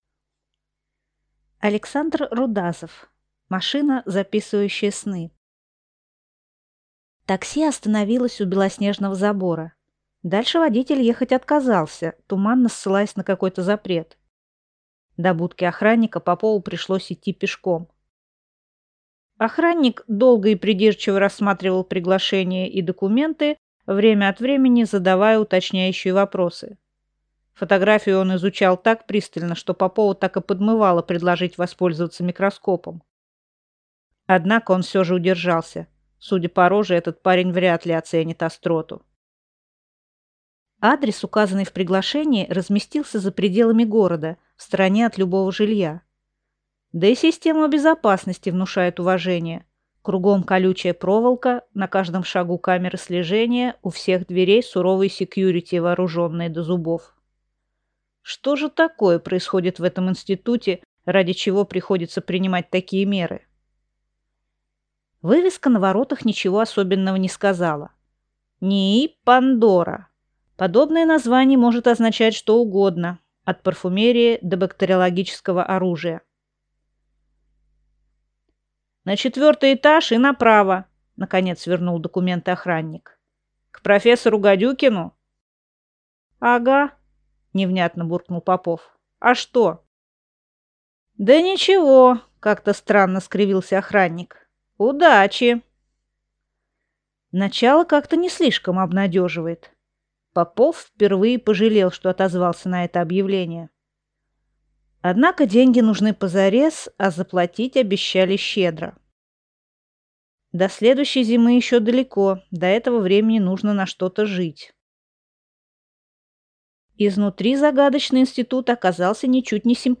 Аудиокнига Машина, записывающая сны | Библиотека аудиокниг